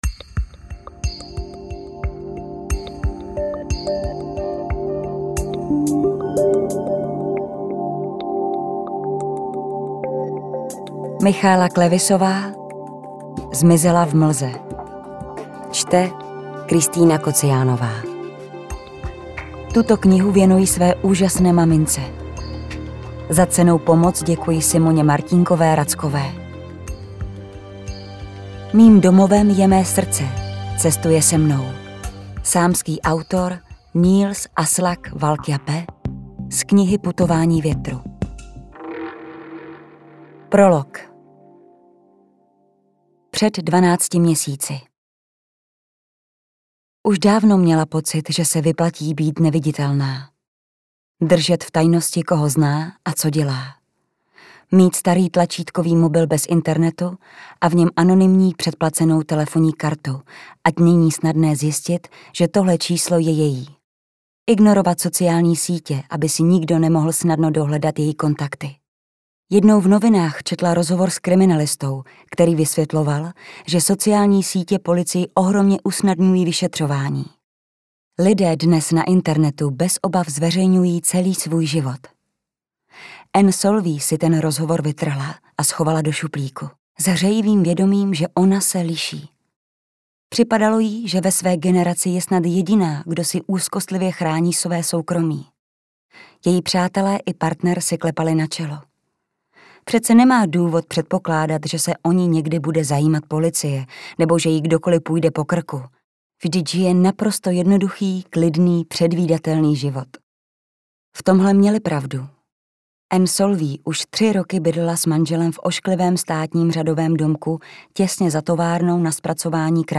Poslouchala jsem jako audioknihu a líbil se mi jak příběh